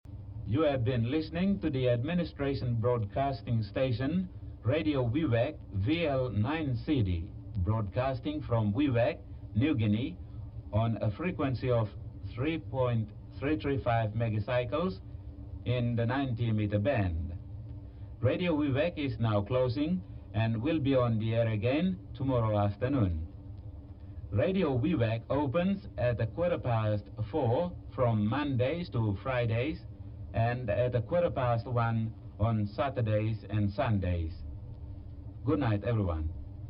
Station ID